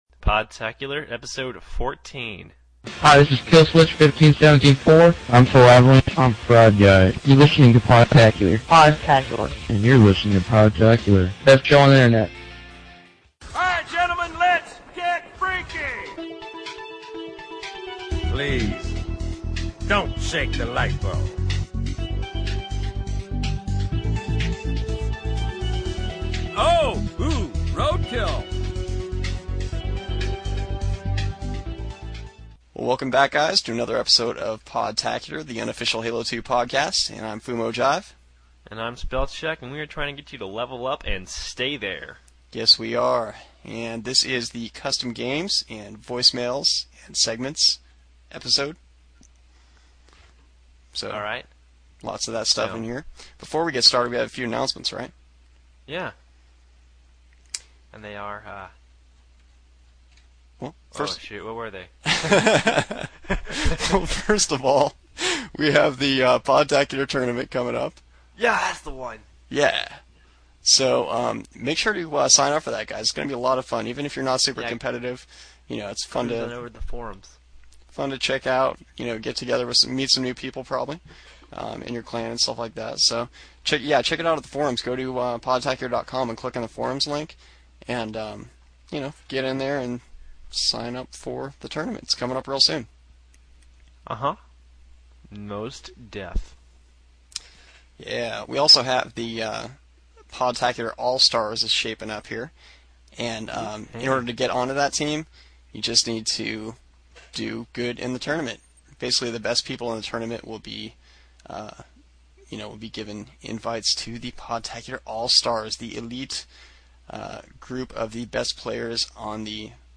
Episode 14: Customs and Call-ins